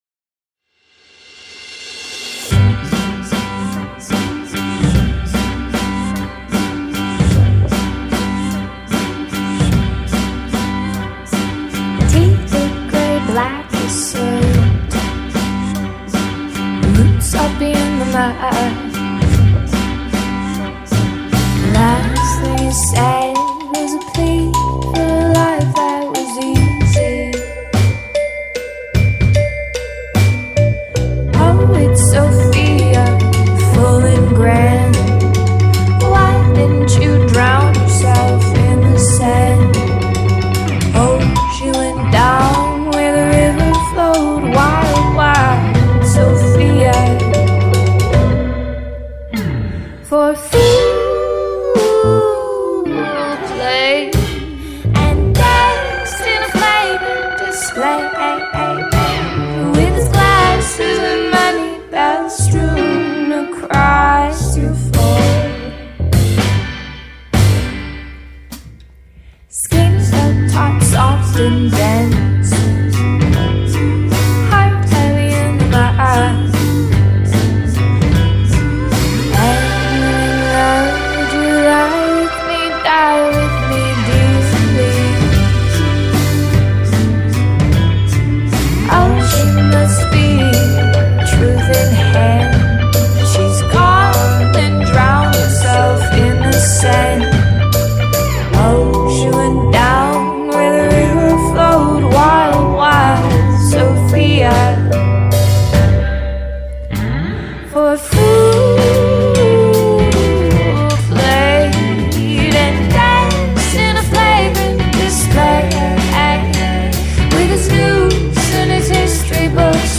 a four-piece Pop/Rock group